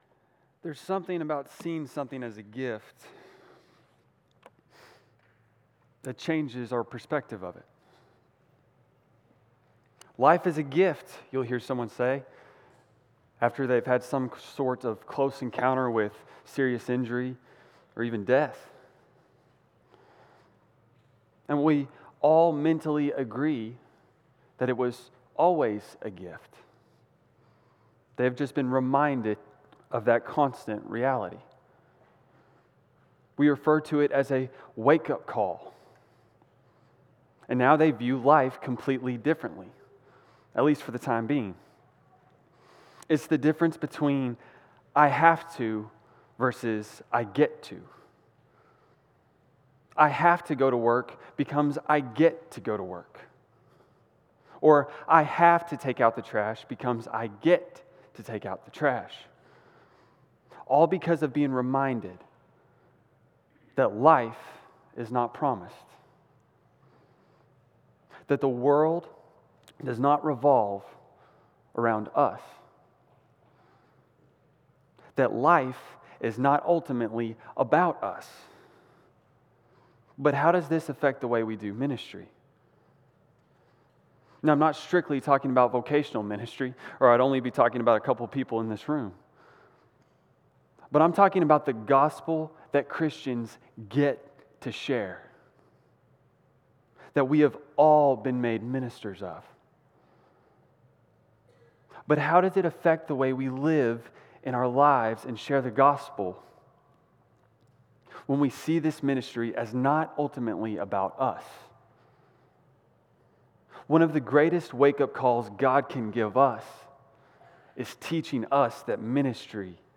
CCBC Sermons